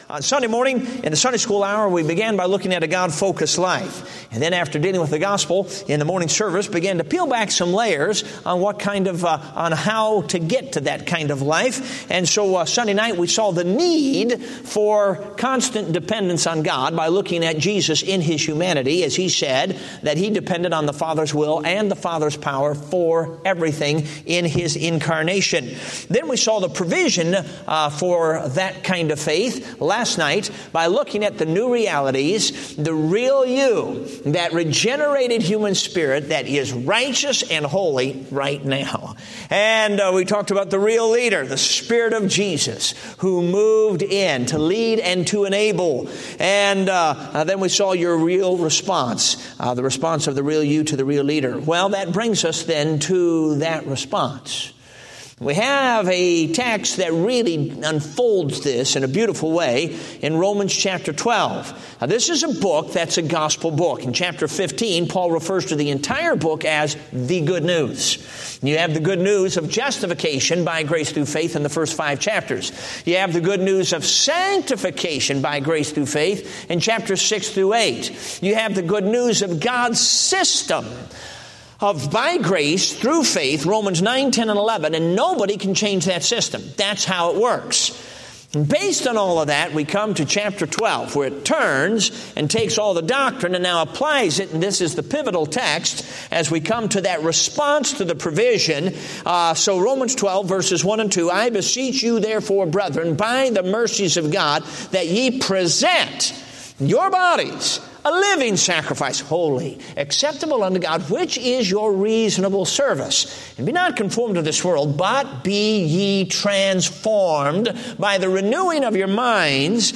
Date: September 8, 2015 (Revival Meeting)